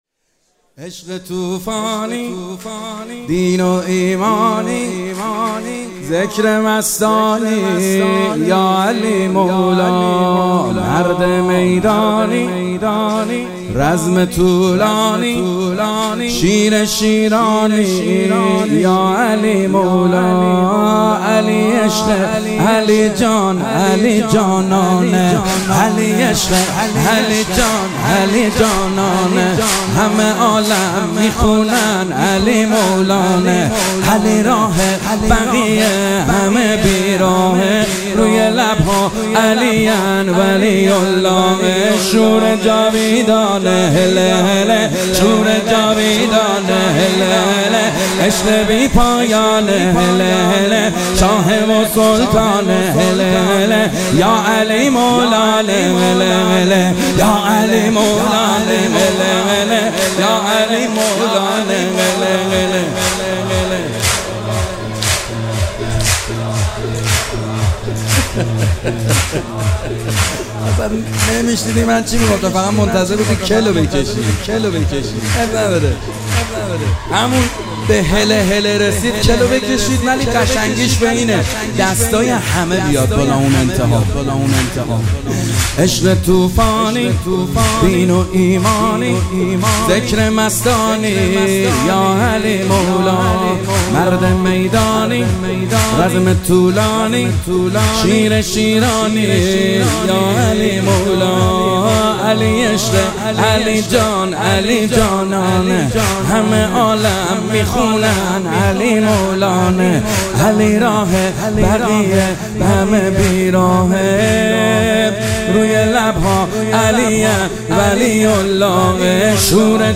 ولادت امیر المومنین - سرود - عشق طوفانی